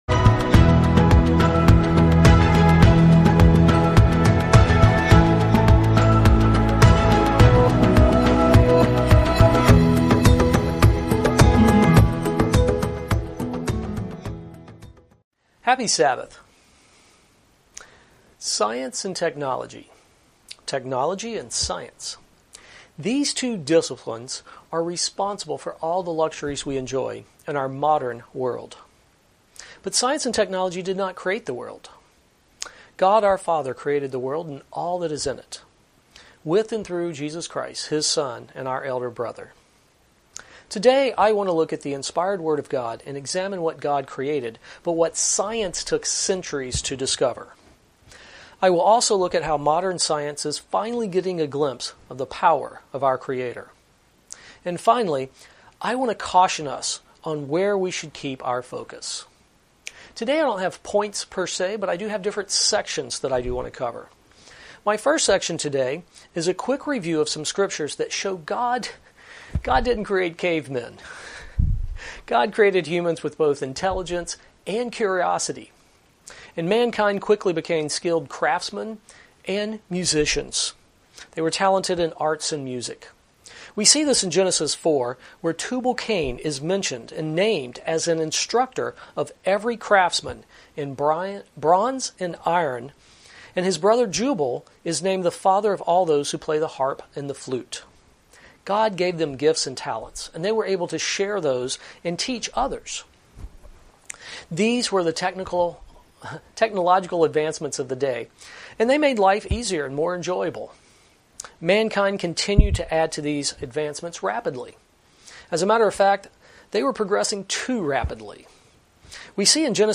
Sermons
Given in Ft. Lauderdale, FL